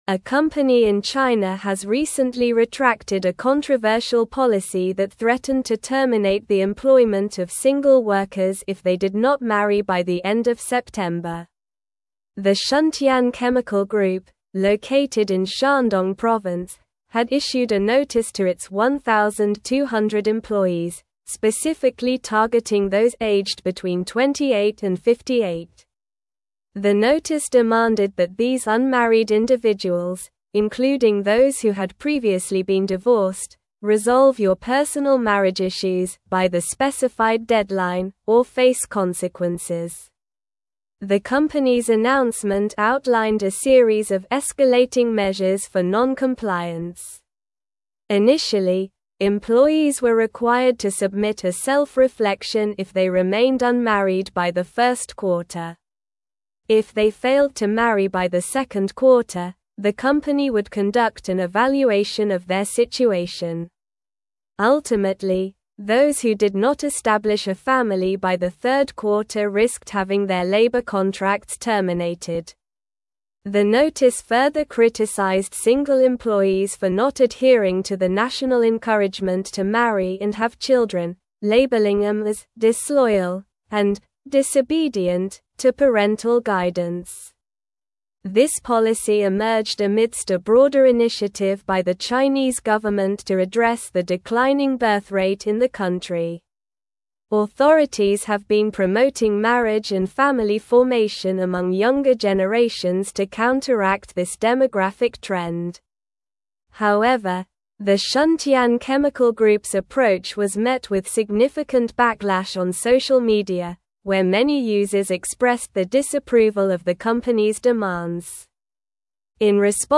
Slow
English-Newsroom-Advanced-SLOW-Reading-Company-Faces-Backlash-Over-Controversial-Marriage-Policy.mp3